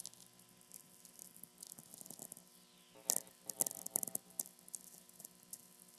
Spring Brook Township, Pennsylvania